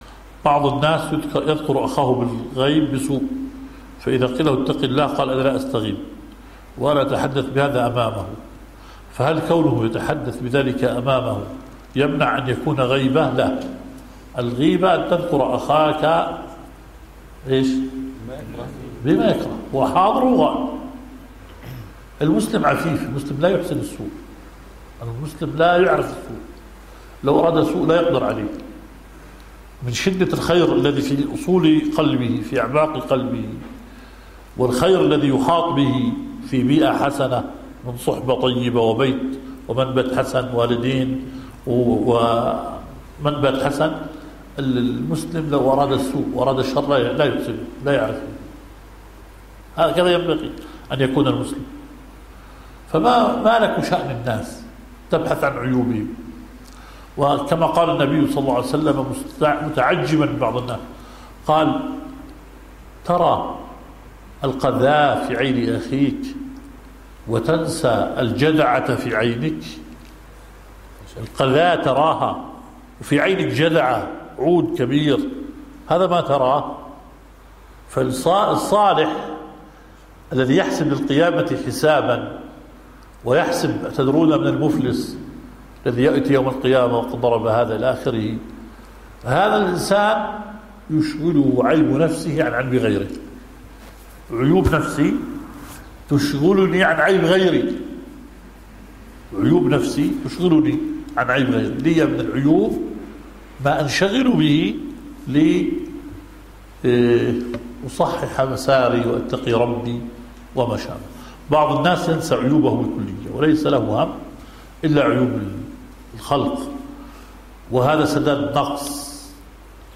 مجلس فتوى